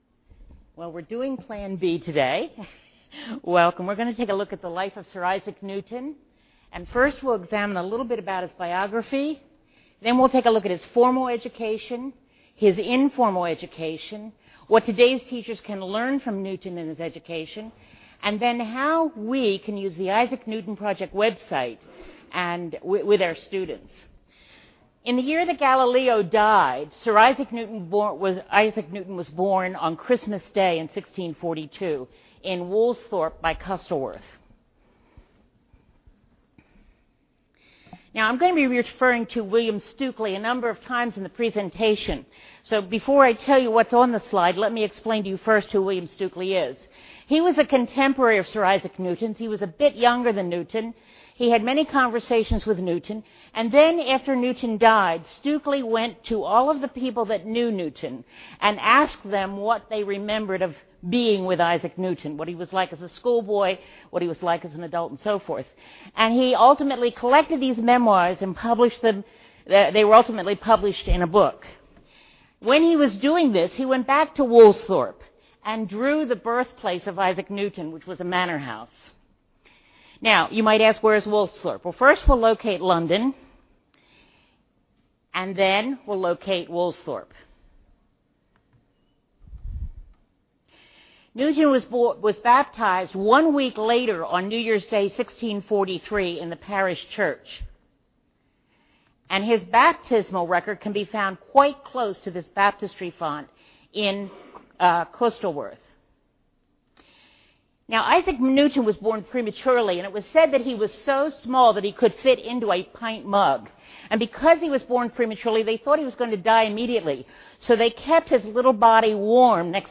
2009 Workshop Talk | 0:52:24 | All Grade Levels, History
The Association of Classical & Christian Schools presents Repairing the Ruins, the ACCS annual conference, copyright ACCS.